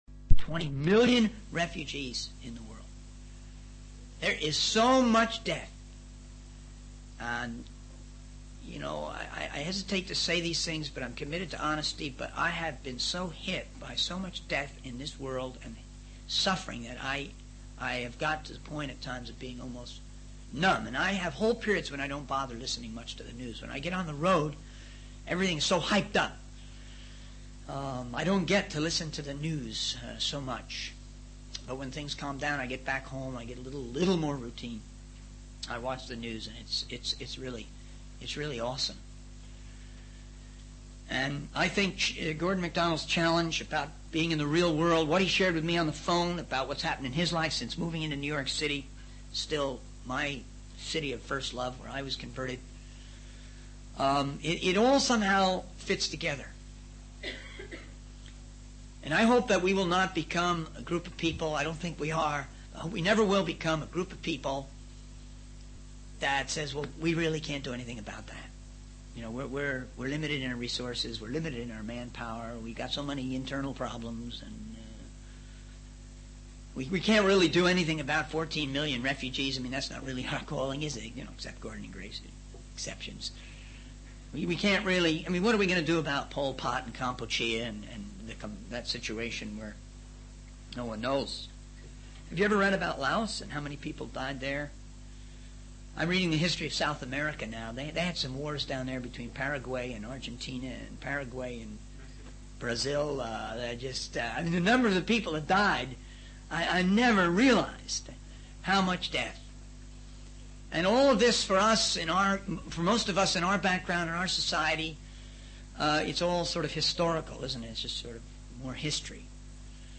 In this sermon, the speaker discusses the importance of taking action when faced with injustice and violence. He emphasizes the need to not simply ignore or dismiss such events, but to actively engage and make a difference.